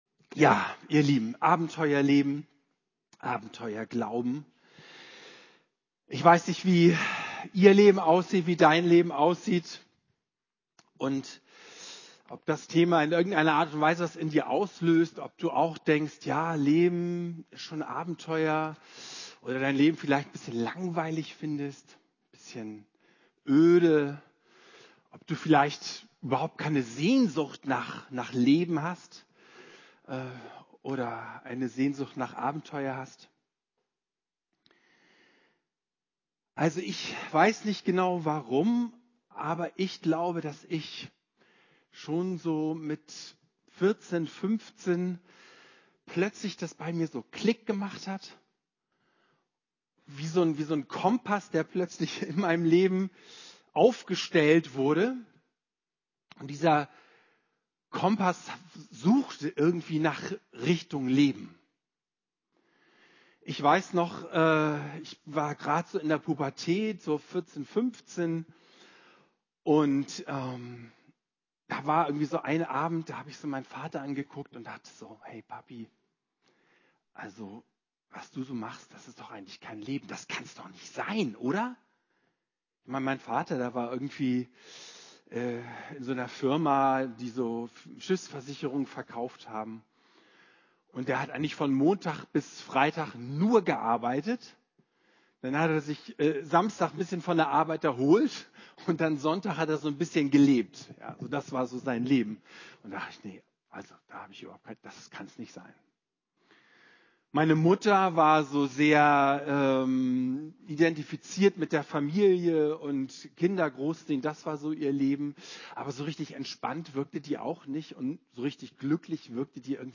Vor 10 Tagen erlebten wir einen Themenabend, der unter die Haut ging.